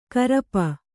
♪ karapa